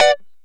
Lng Gtr Chik Min 07-B2.wav